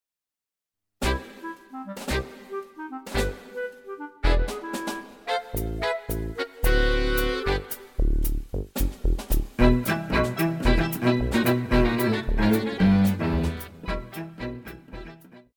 大提琴
乐团
演奏曲
世界音乐,融合音乐
仅伴奏
没有主奏
没有节拍器
曲子附有演奏版和不含主旋律的伴奏版。